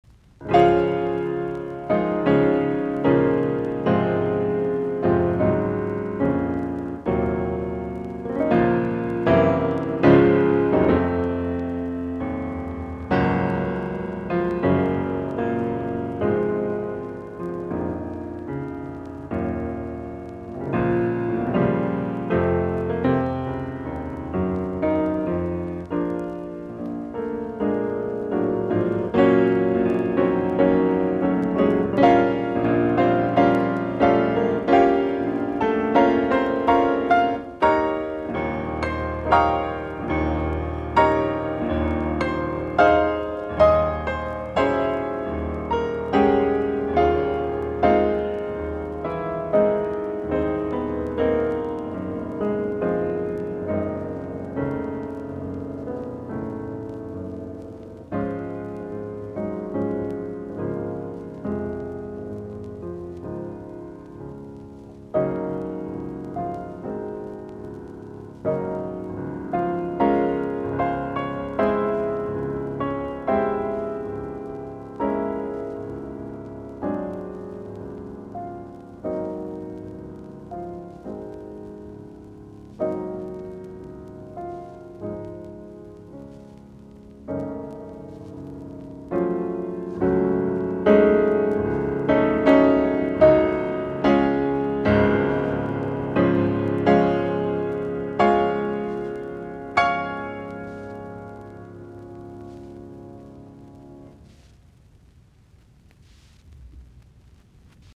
Soitinnus: Piano.